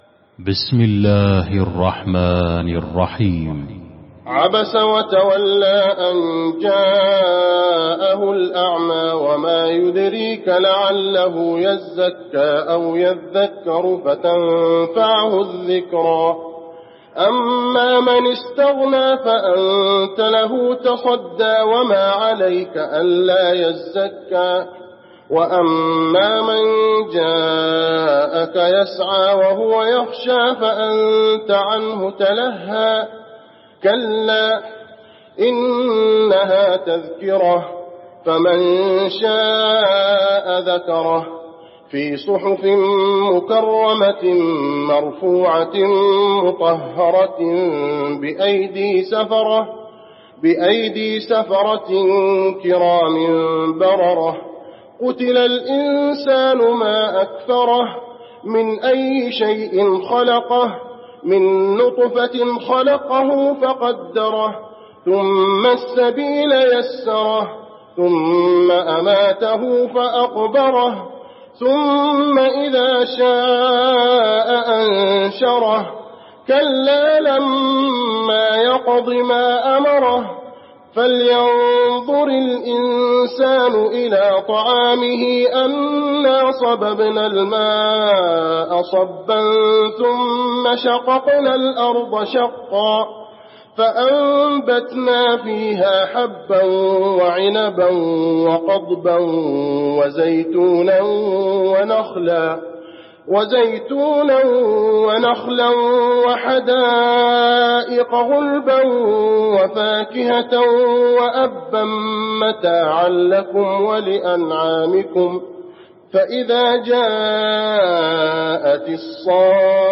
المكان: المسجد النبوي عبس